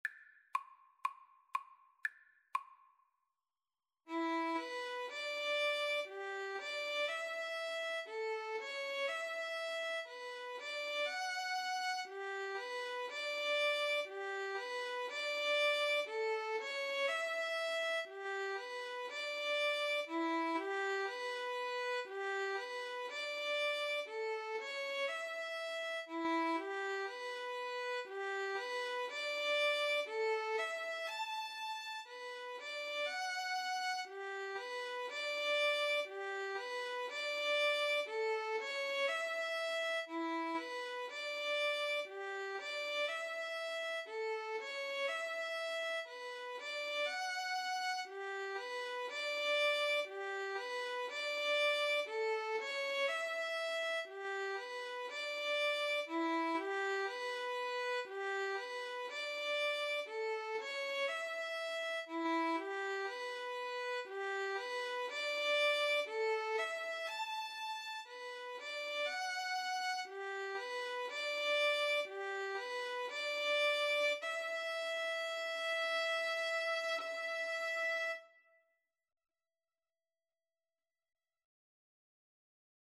Andante Cantabile = c. 60
Violin-Flute Duet  (View more Easy Violin-Flute Duet Music)
Classical (View more Classical Violin-Flute Duet Music)